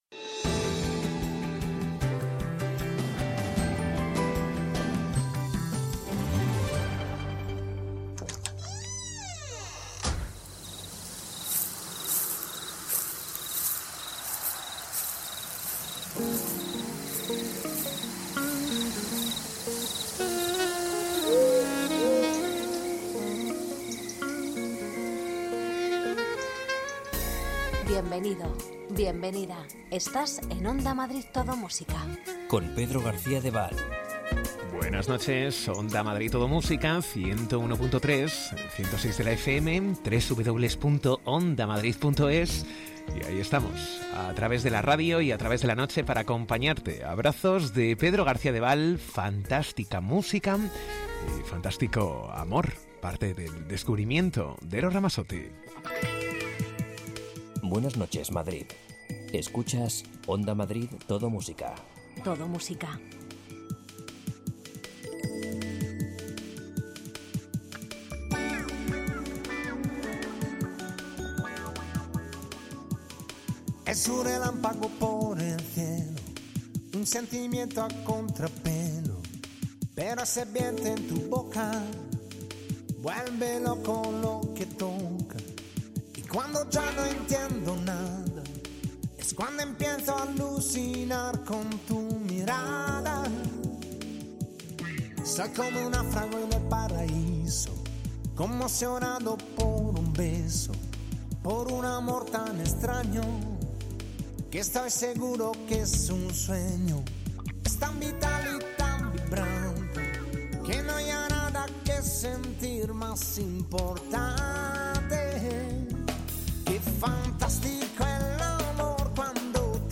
Los mejores lentos
Ritmo tranquilo, sosegado, sin prisas...